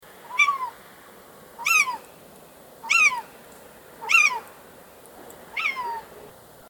دانلود صدای روباه 2 از ساعد نیوز با لینک مستقیم و کیفیت بالا
جلوه های صوتی